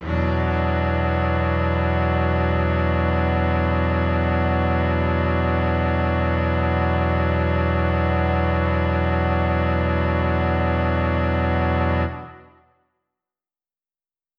SO_KTron-Cello-E7:9.wav